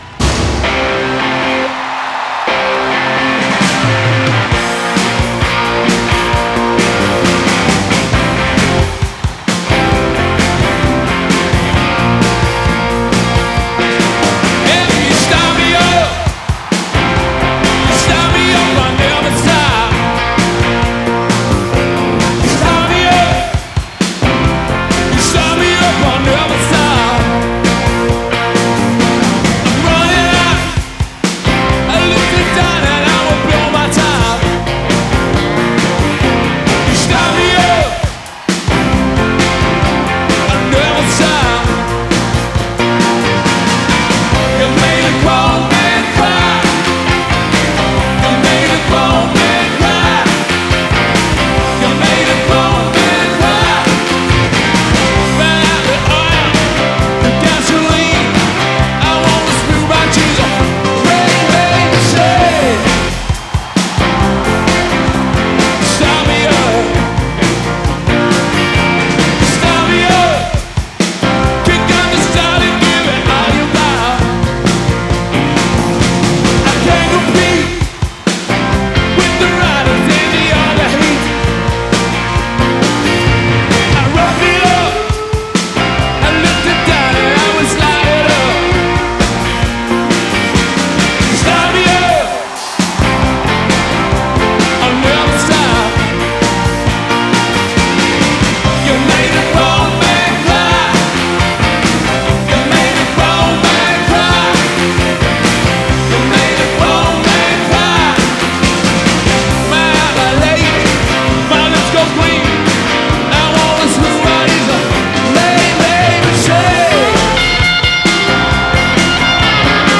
Rock and Roll, Blues Rock